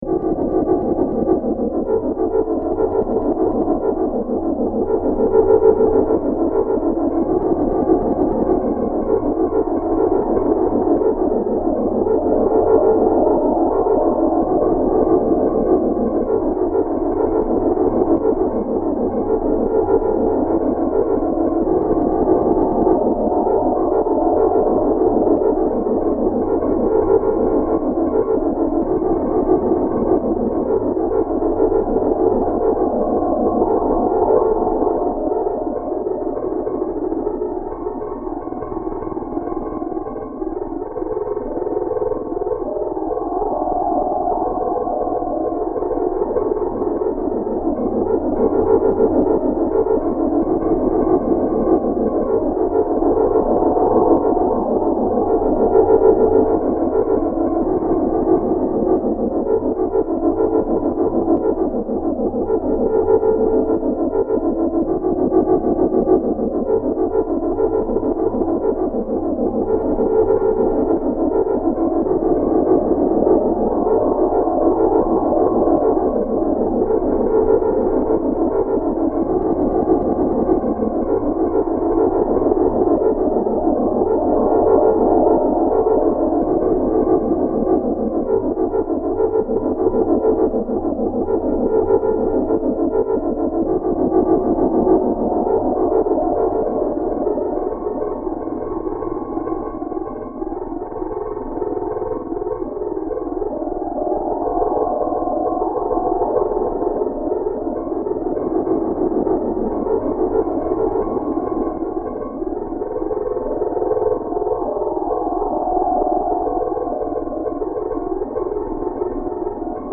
Pieza de Dark Ambient
Música electrónica
ambiental
melodía
sintetizador